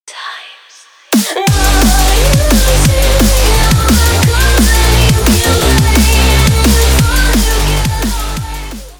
• Качество: 320, Stereo
красивый женский голос
быстрые
drum n bass
Классный драм-н-бэйс